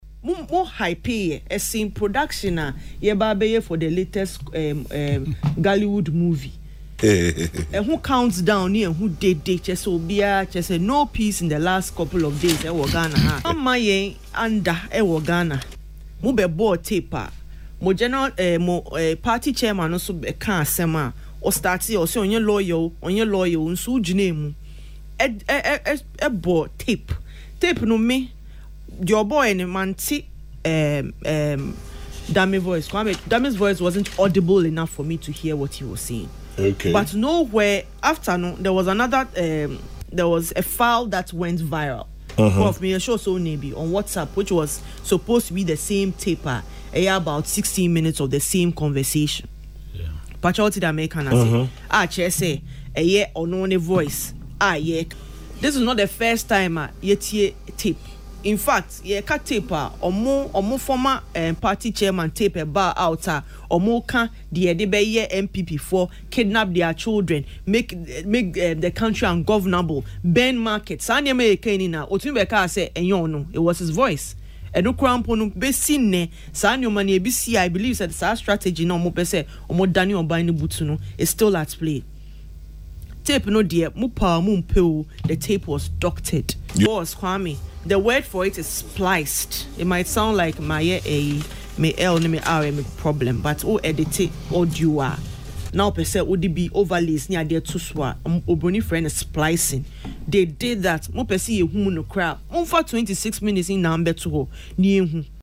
It was spliced and they can’t deny it,” she stated in an interview on Accra-based Peace FM.